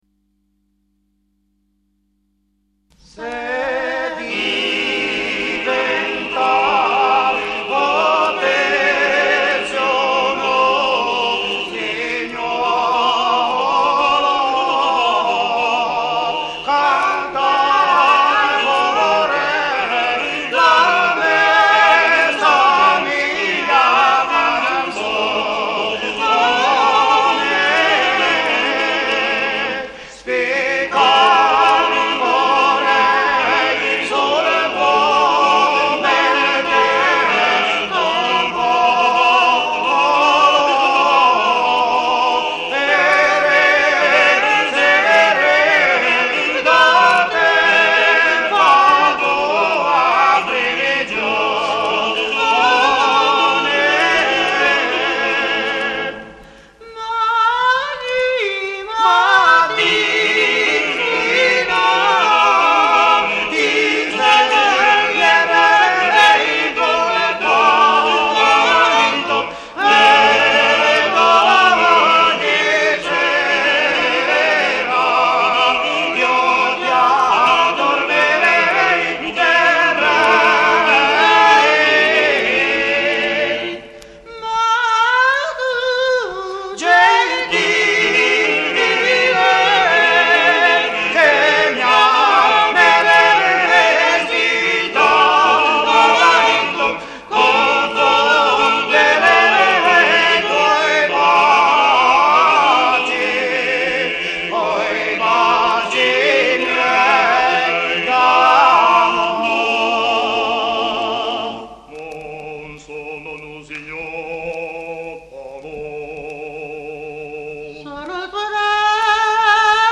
RACCOLTA DI CANTI TRADIZIONALI E SCENE COMICHE